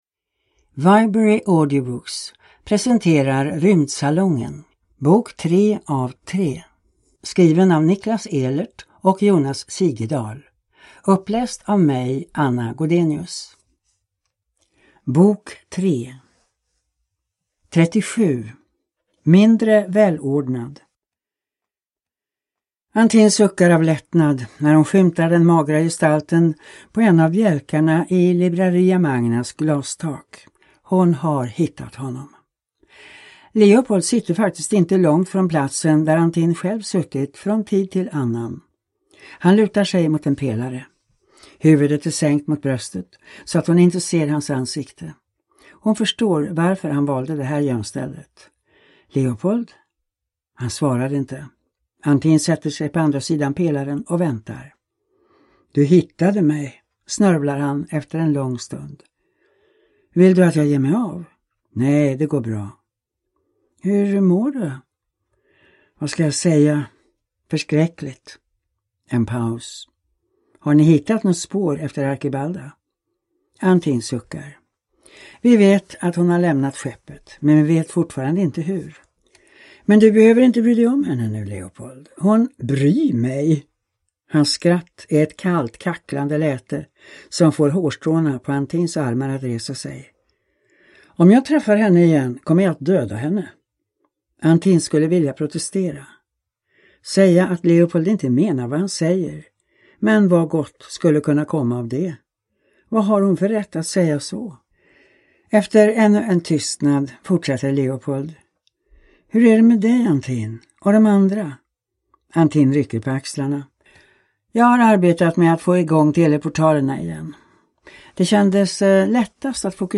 Rymdsalongen: Del 3 – Ljudbok